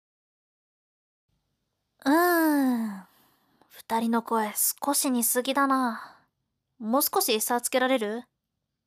せりふ